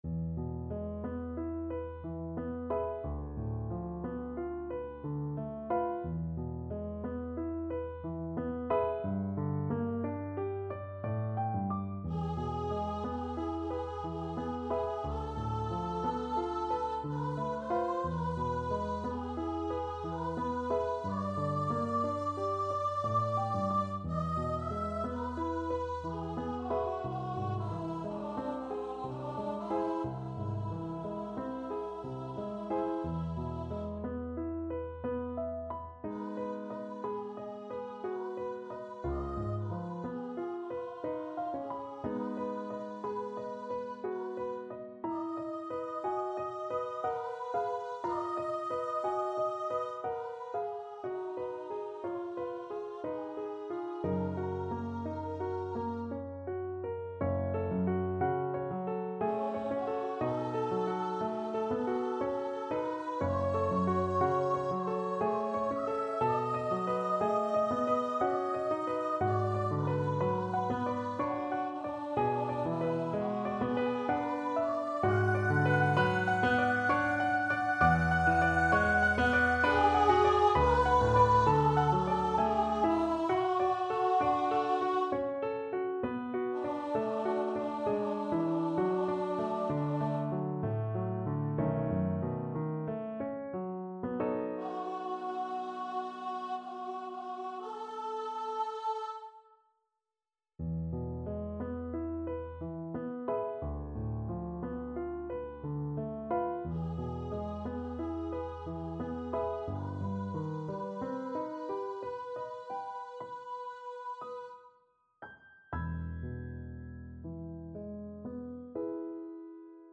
3/4 (View more 3/4 Music)
C5-F#6
Andante ma non troppo =60